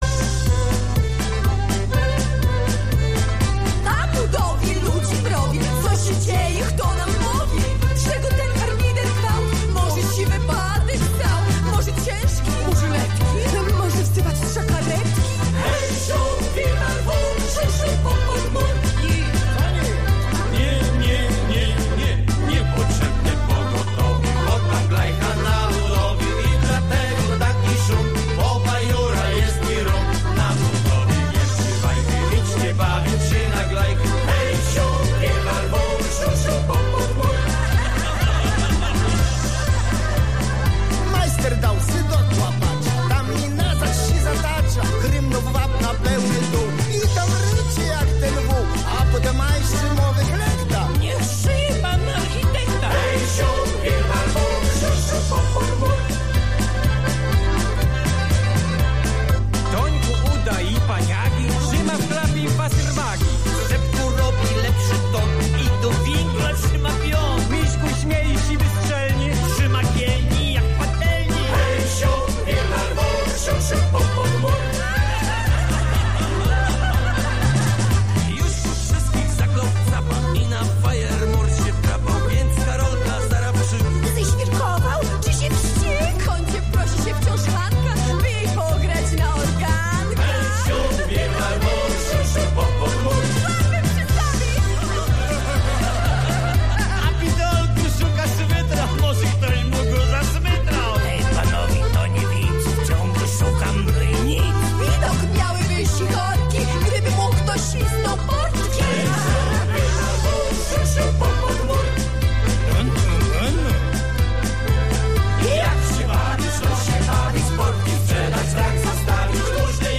co to za piosenka z radia katowice?
piosenka sląska